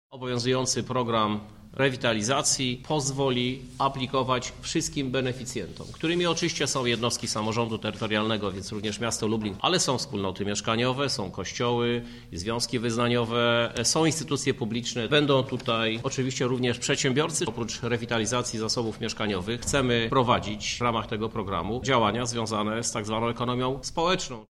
Kto ma możliwość starania się o pieniądze mówi prezydent Krzysztof Żuk.